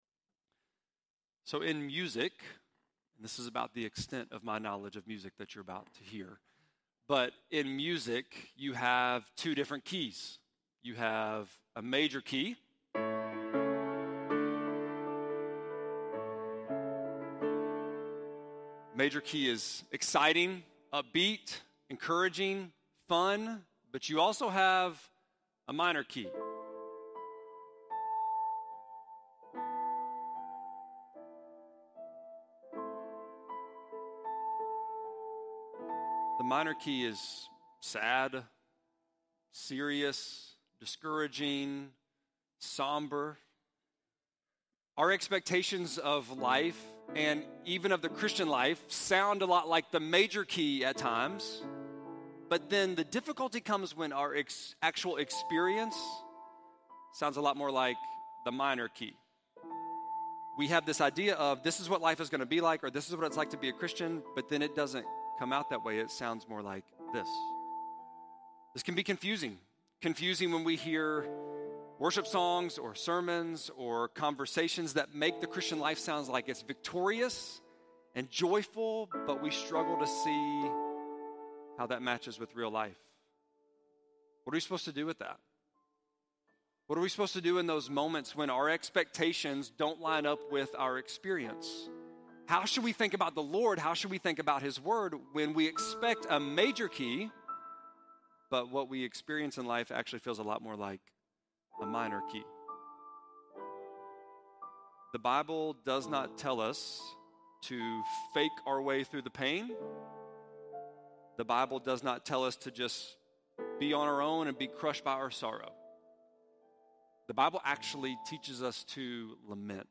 7.7-sermon.mp3